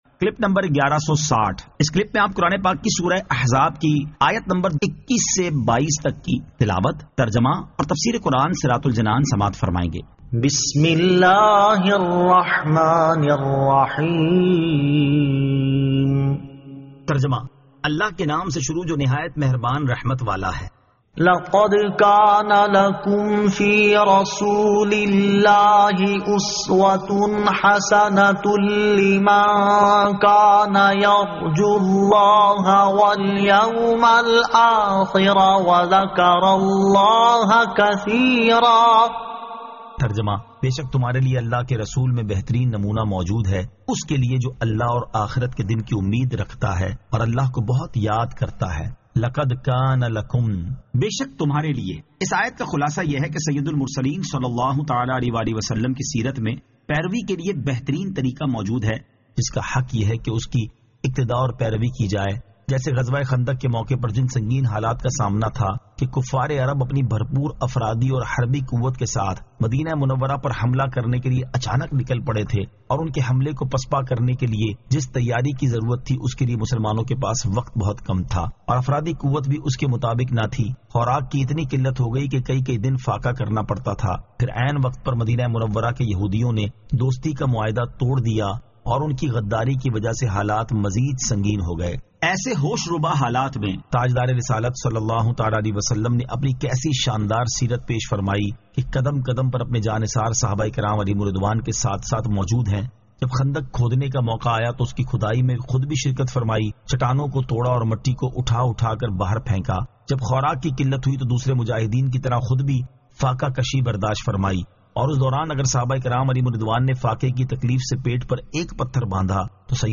Surah Al-Ahzab 21 To 22 Tilawat , Tarjama , Tafseer